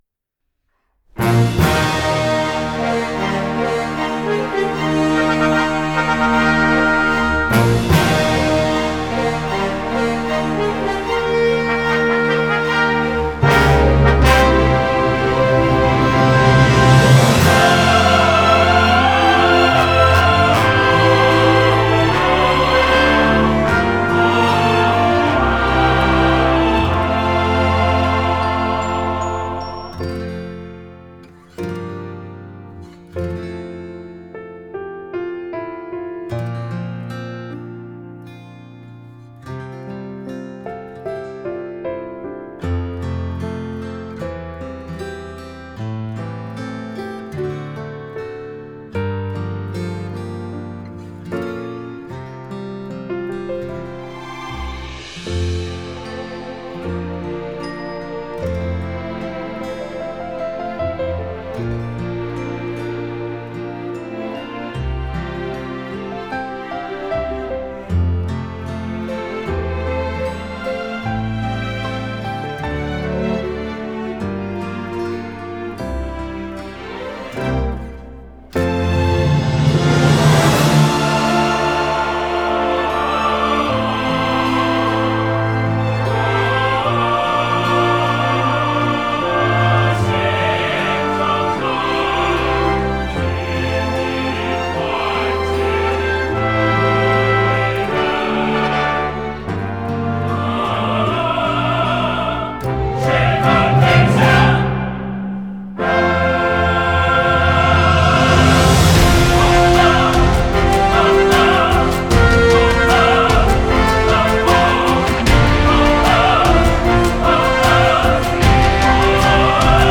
将充满温情的民歌曲调与气势恢弘的交响乐曲融合起来
明快厚重、催人奋进